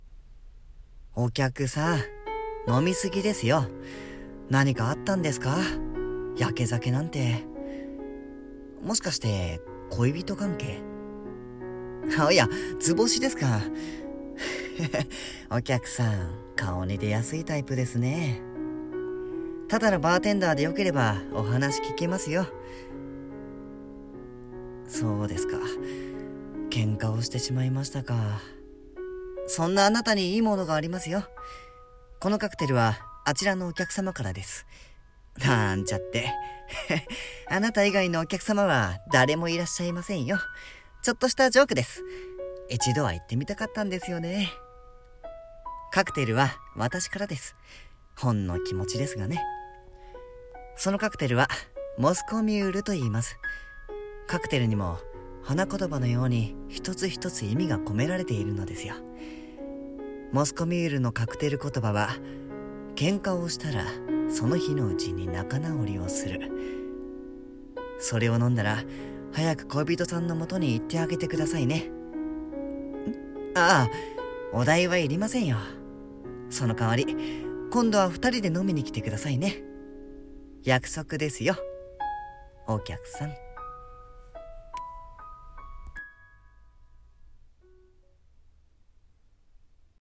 【声劇】飲み過ぎですよ。 バーテンダー:○○